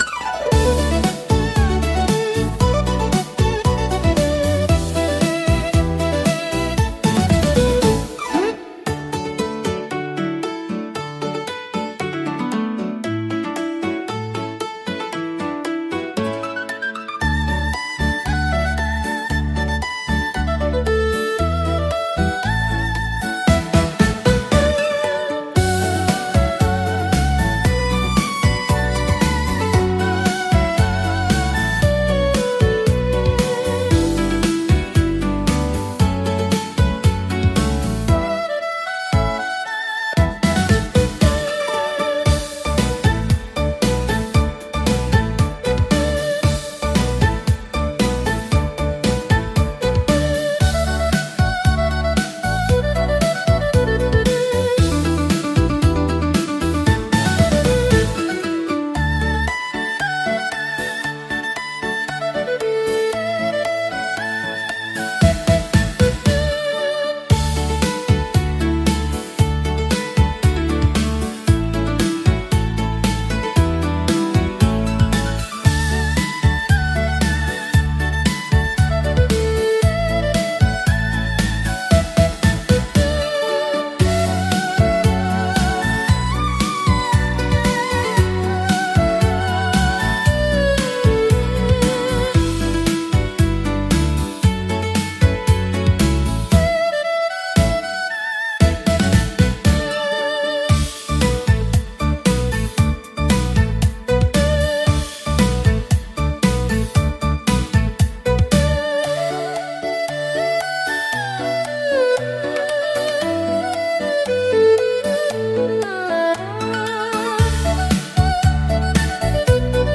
聴く人の心に深い感動と癒しを届ける優雅で情緒豊かなジャンルです。